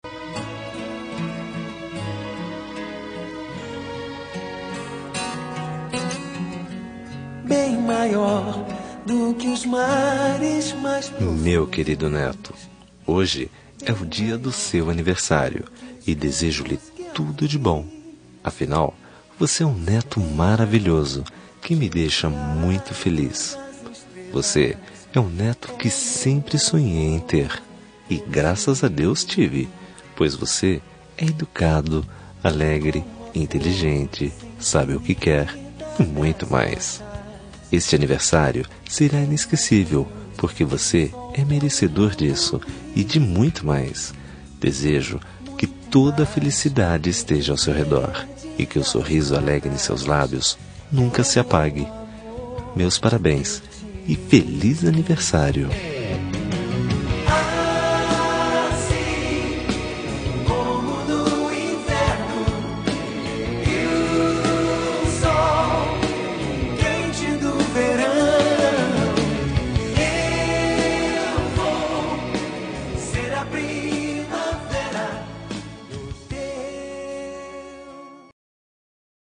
Aniversário de Neto – Voz Masculina – Cód: 131062